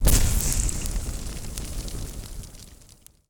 poly_explosion_incendiary.wav